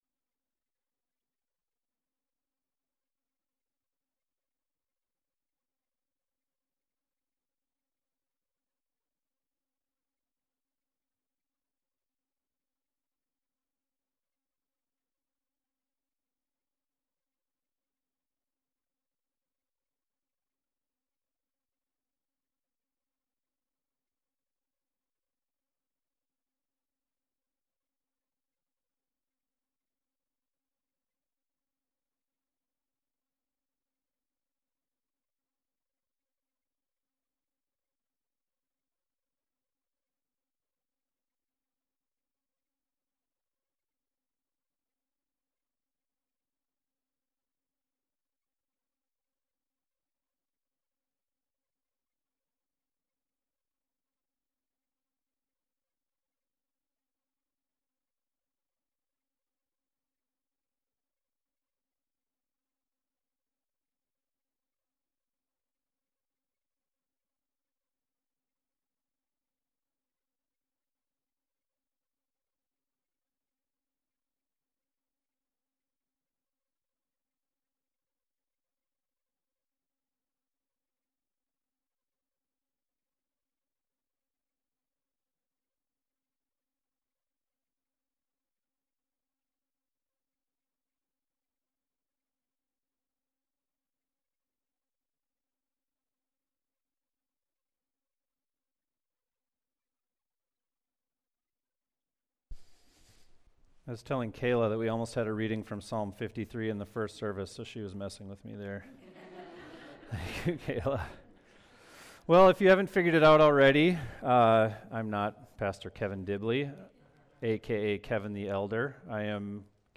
Sermons | Waterbrooke Christian Church